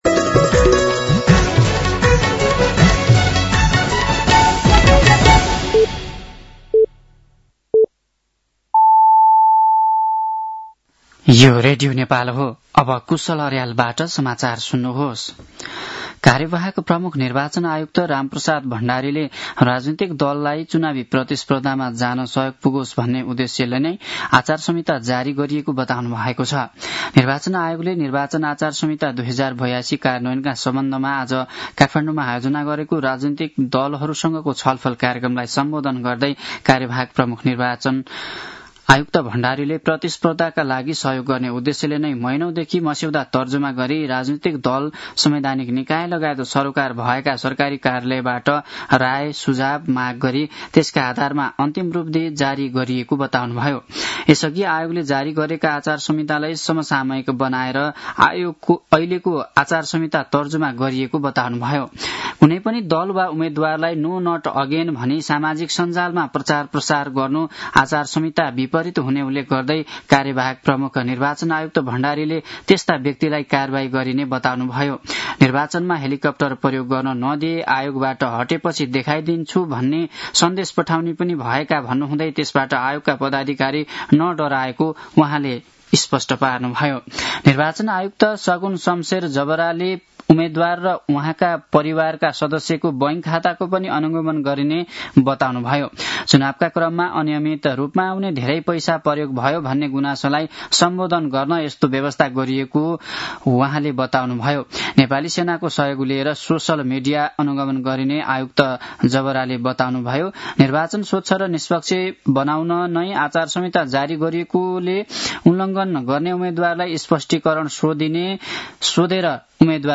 साँझ ५ बजेको नेपाली समाचार : २ माघ , २०८२